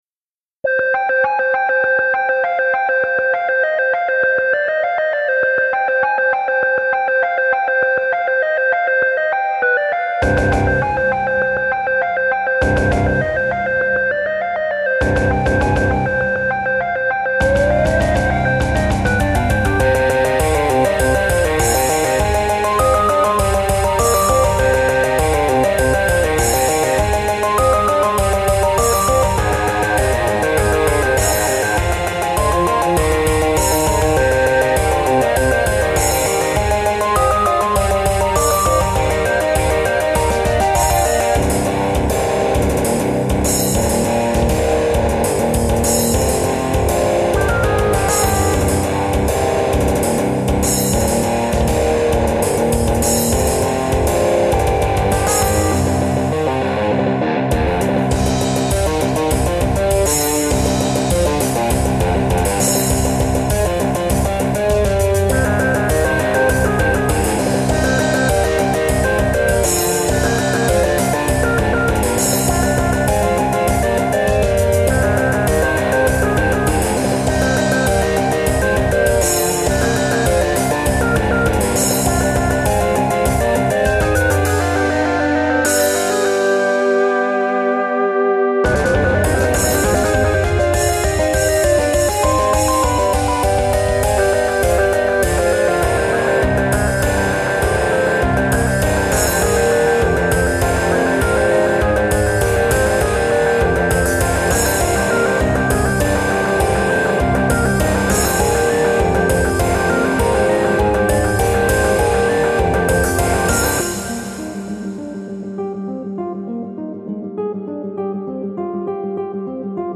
Music / Rock
Metal-techno!
Very creative mix of metal and electronic.